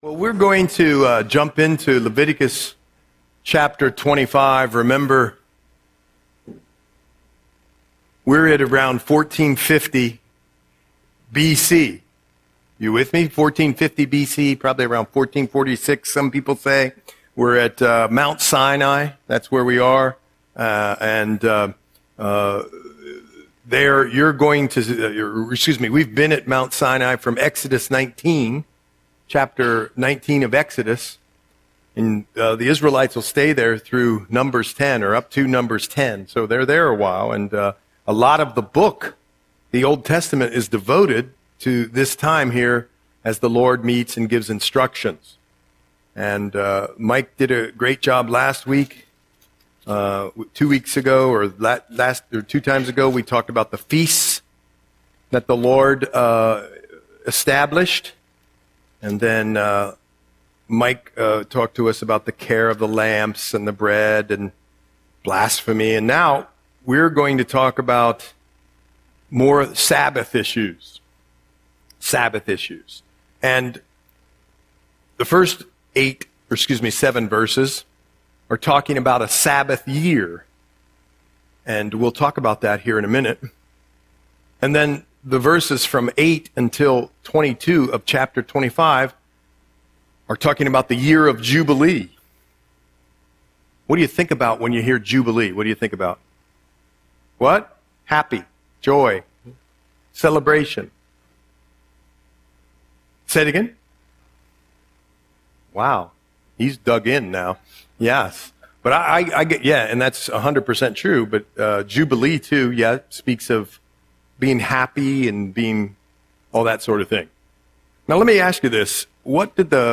Audio Sermon - January 21, 2025